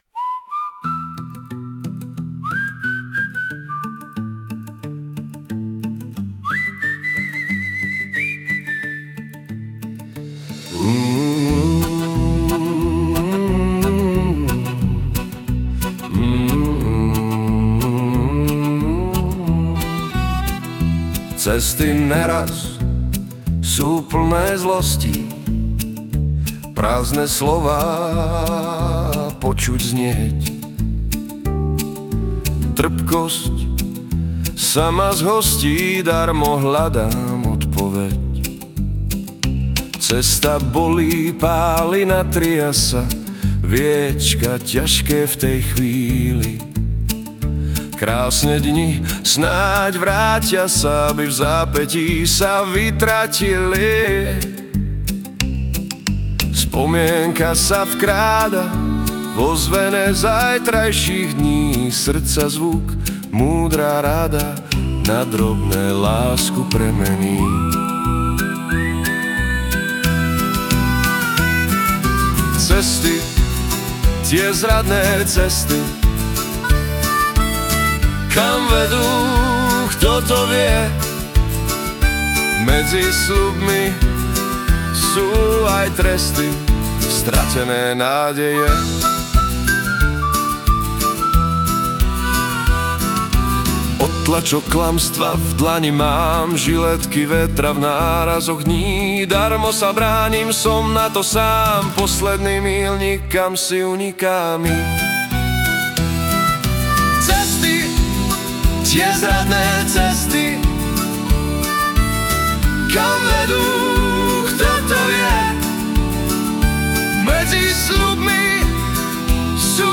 Hudba a spev AI
Balady, romance » Ostatní